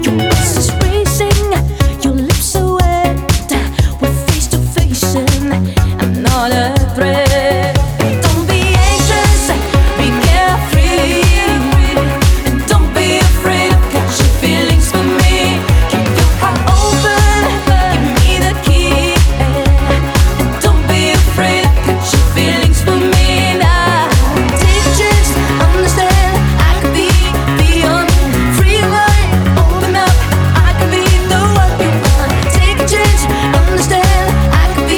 Жанр: R&B / Соул / Диско